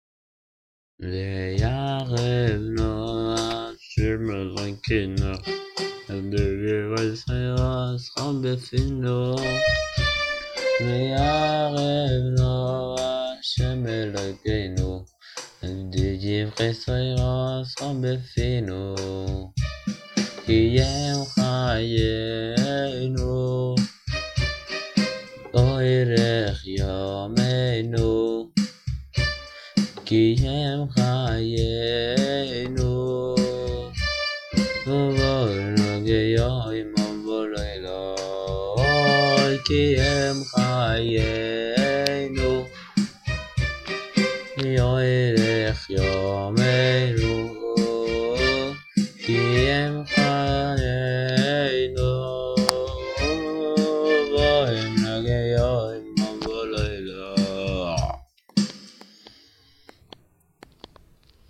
מחילה על הזייפנות והקול של הסיגריות